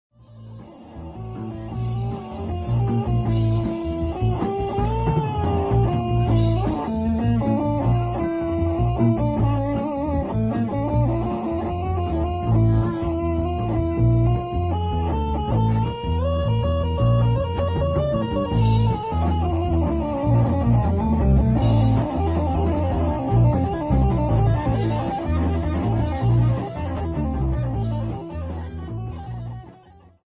The band played hard rock and blues.